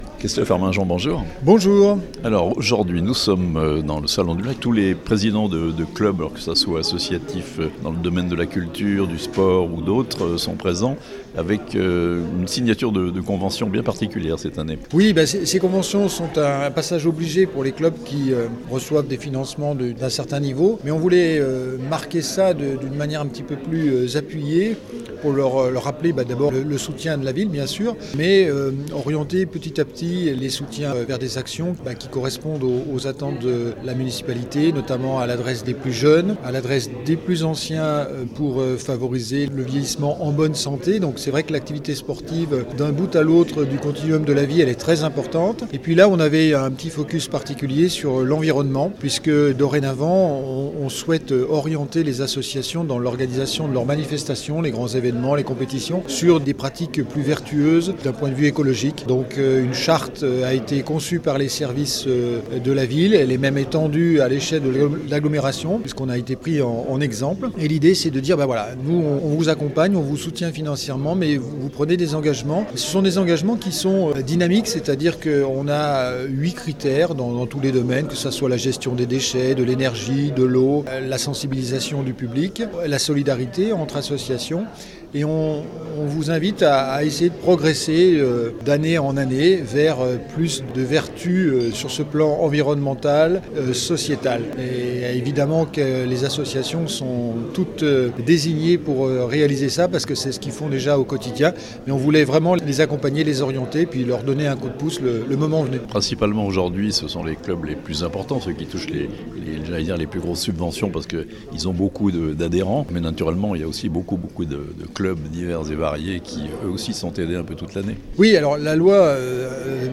Thonon : une convention d'objectifs entre la Ville et les associations sportives et culturelles (interview)
Les élus de Thonon ont souhaité ajouter également à cette convention traditionnelle diverses clauses sociales et environnementales que détaille ici Christophe Arminjon, Maire de la ville.